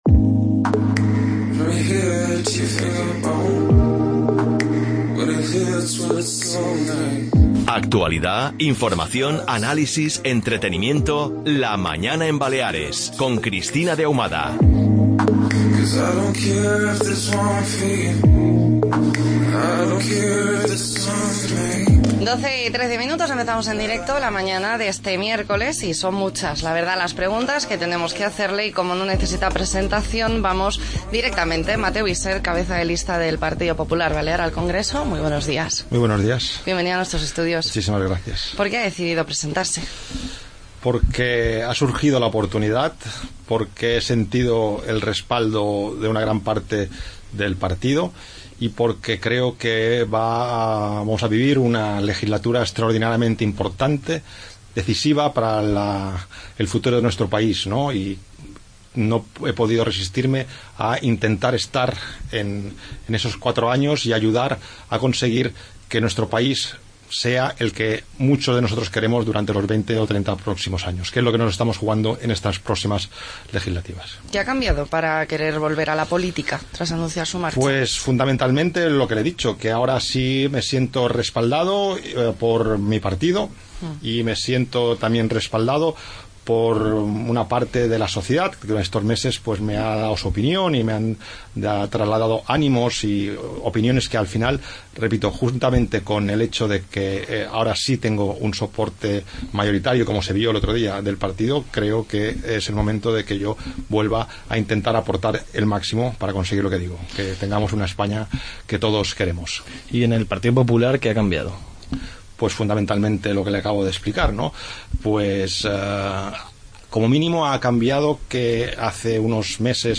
Entrevista a Mateu Isern
AUDIO: El cabeza de lista del PP balear al Congreso, Mateu Isern, responde en La Mañana en Baleares sobre toda la actualidad del partido:...